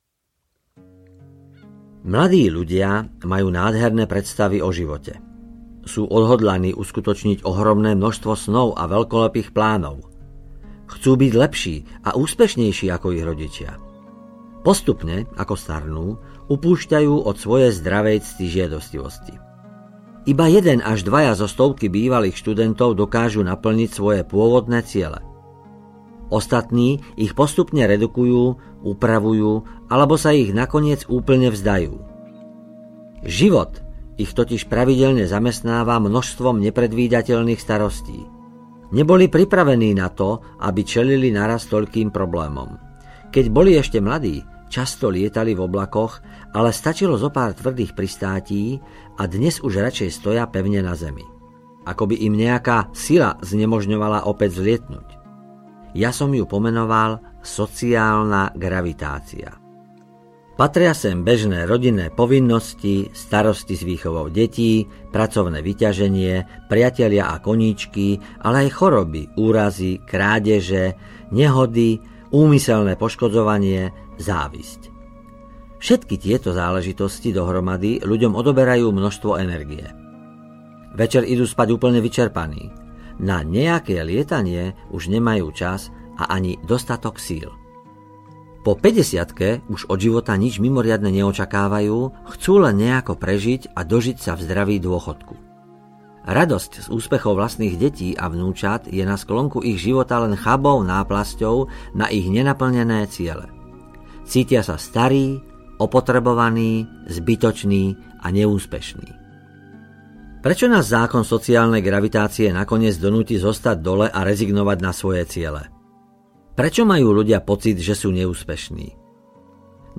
Tajomstvo úspechu audiokniha
Ukázka z knihy